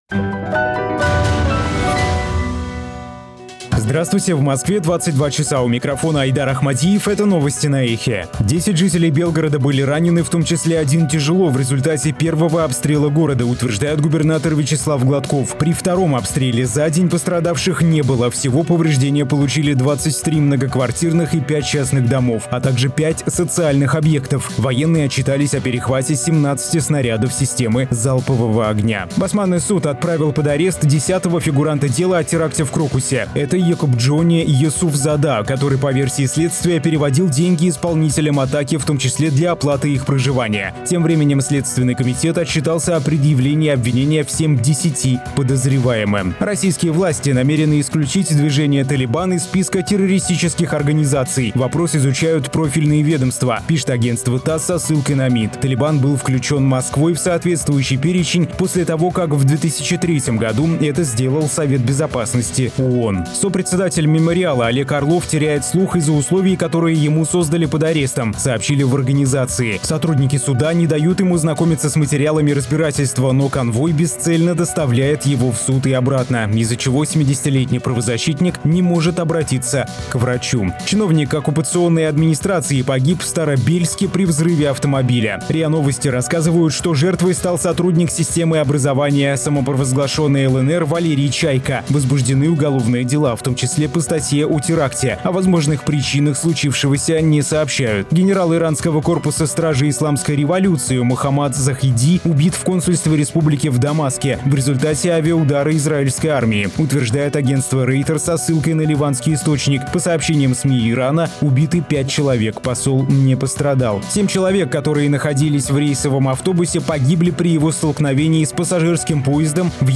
Слушайте свежий выпуск новостей «Эха»
Новости 22:00